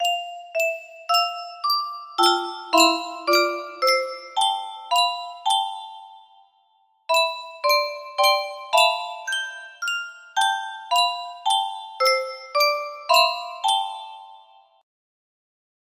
<3 (o3o) <3 <3 music box melody